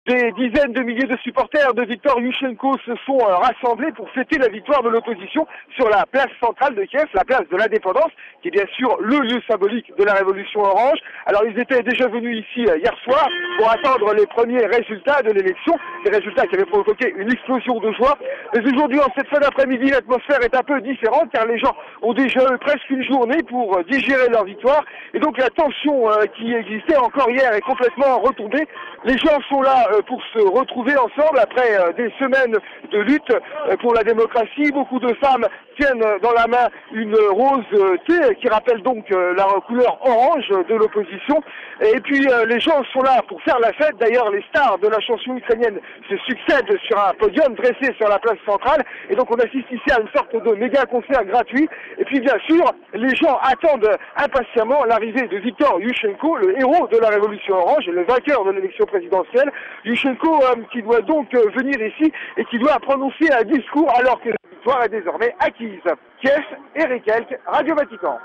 Lundi soir les opposants se sont retrouvés sur la place de l’indépendance à Kiev pour écouter leur héros.